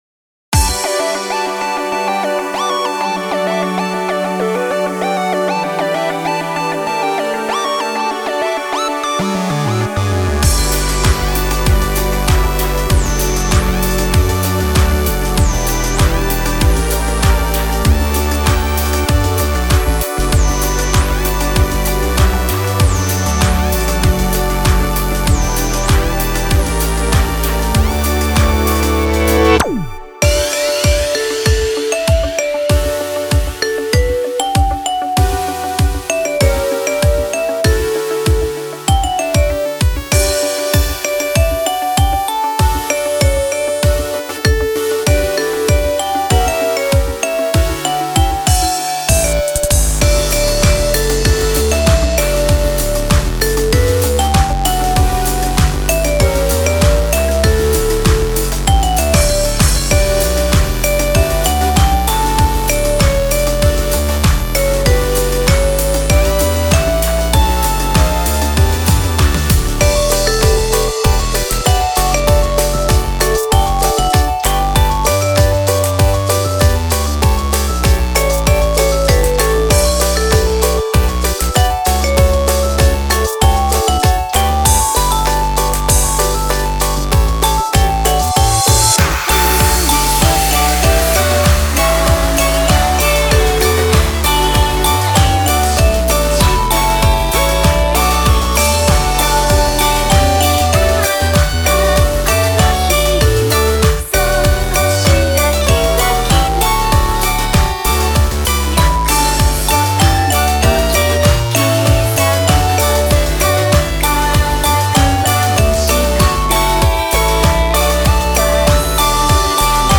以上のmp3は、全てガイドメロディが入ったものになっております。